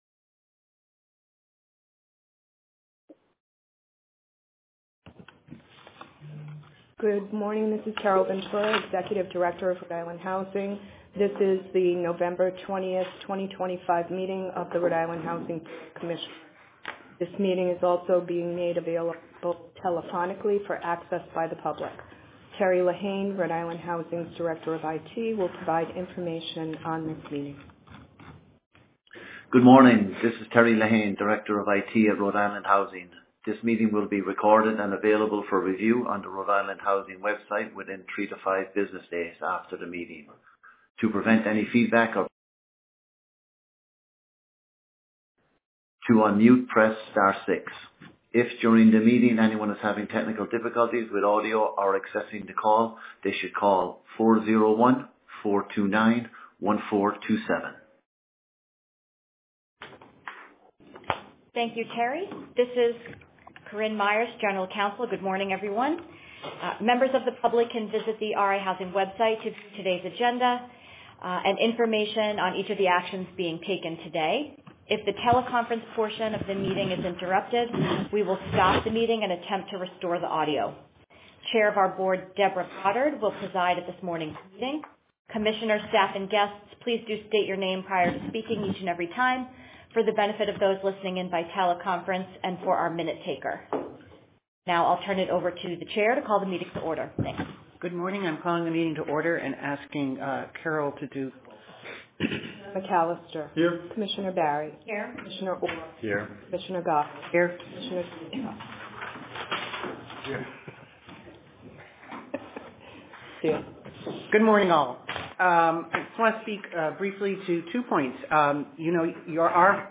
Recording of RIHousing Board of Commissioners Meeting: 11.20.2025